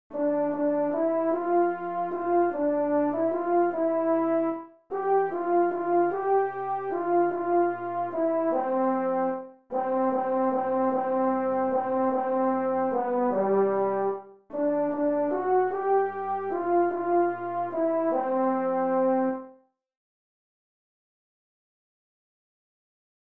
Chorproben MIDI-Files 496 midi files